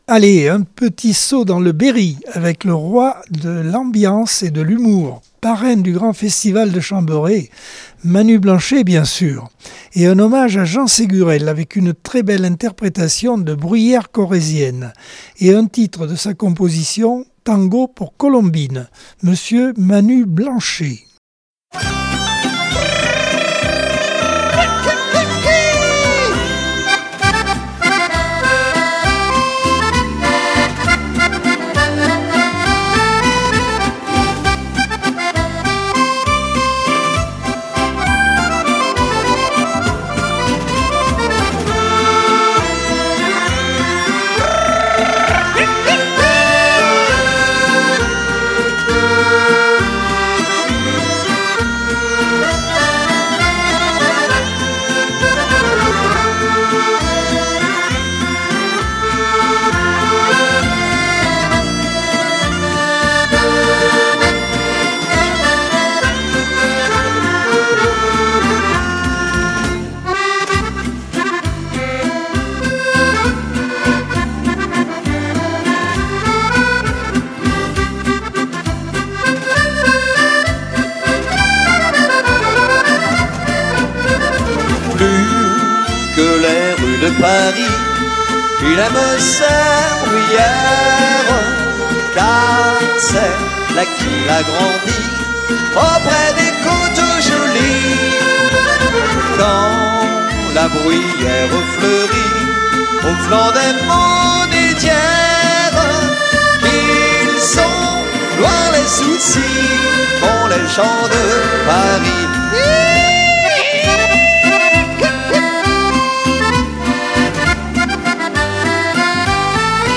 Accordeon 2024 sem 49 bloc 3 - Radio ACX